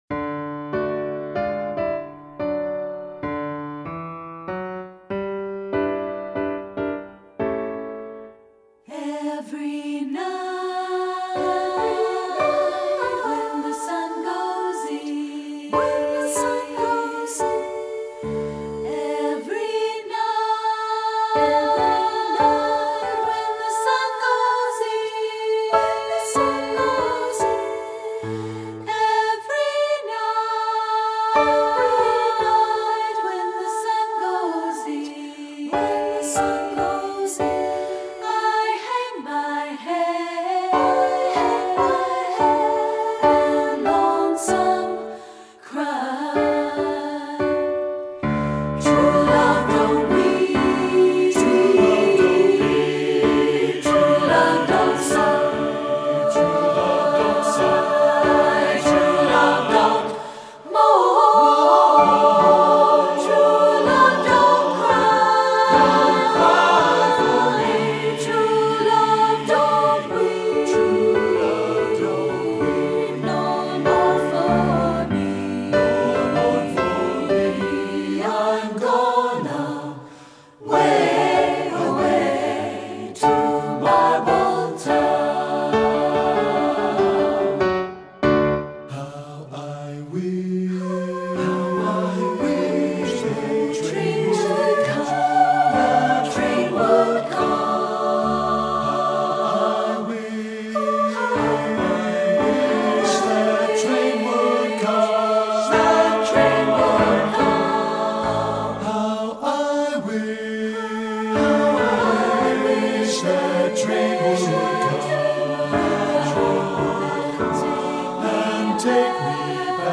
Composer: American Folk Song
Voicing: SATB